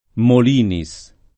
Molinis [ mol & ni S ] top. (Friuli)